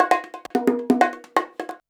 133BONG02.wav